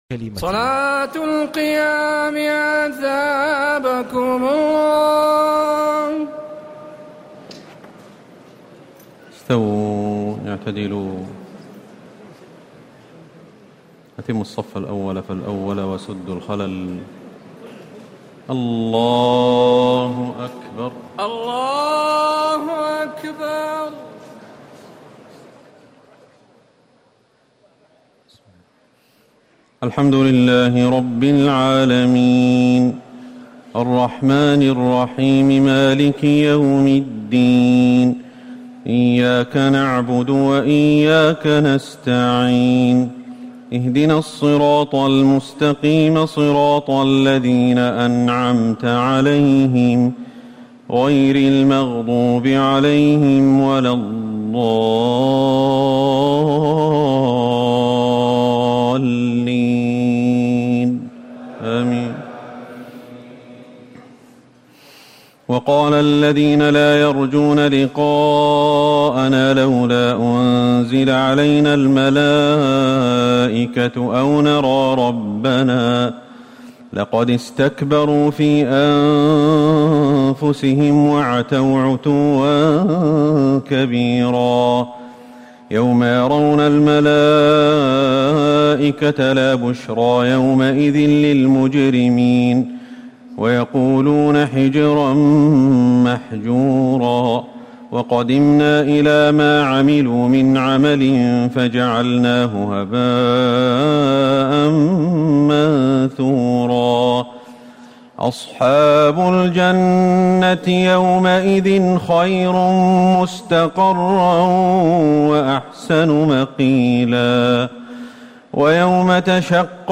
تراويح الليلة الثامنة عشر رمضان 1439هـ من سورتي الفرقان (21-77) و الشعراء (1-122) Taraweeh 18 st night Ramadan 1439H from Surah Al-Furqaan and Ash-Shu'araa > تراويح الحرم النبوي عام 1439 🕌 > التراويح - تلاوات الحرمين